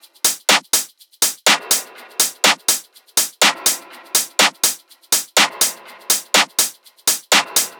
Index of /m8-backup/M8/Samples/Loops/Seismic Loops/Top Loops
SD_SEISMIC_tops_01_Santorini_123.wav